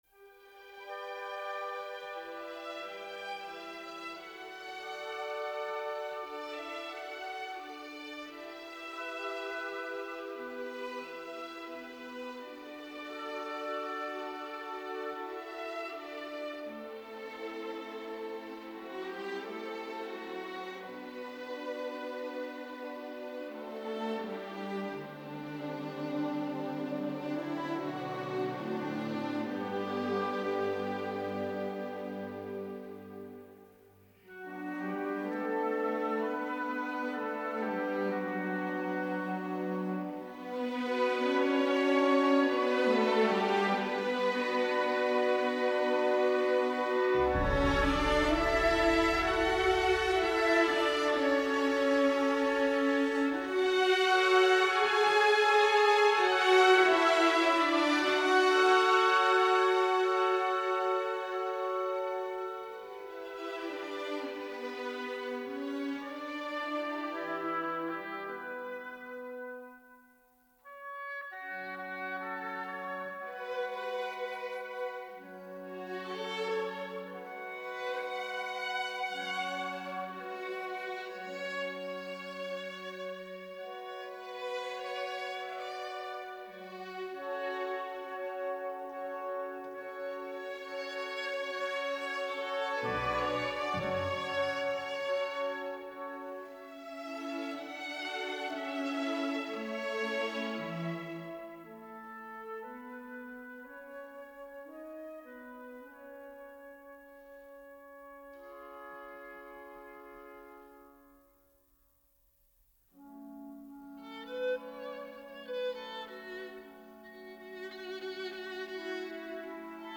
* Musica: “Un bel di vedremo”  versión sinfonica de Madame Butterfly, de Giacomo Puccini.